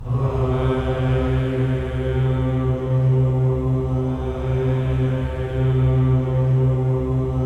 VOWEL MV02-R.wav